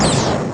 ENGINE02.WAV